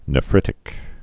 (nə-frĭtĭk)